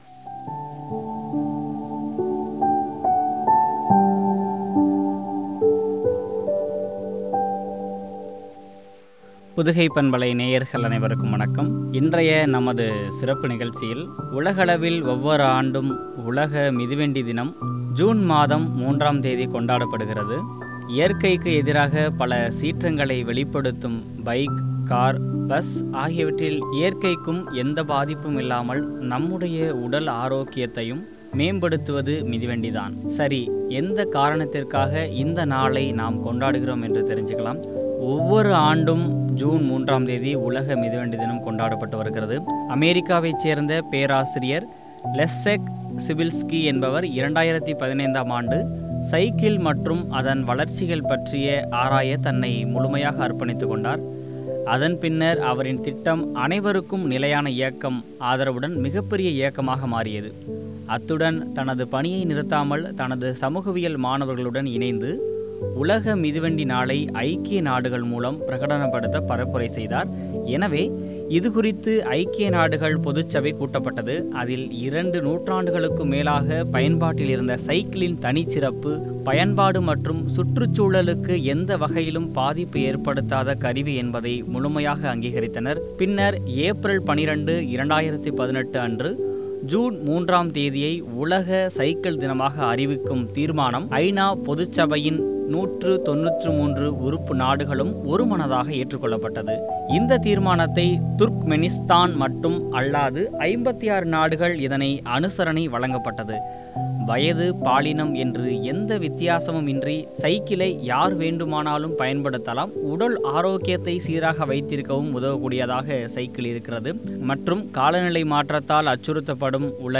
பயன்பாடுகளும் குறித்து வழங்கிய உரையாடல்.